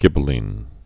(gĭbə-lēn, -līn, -lĭn)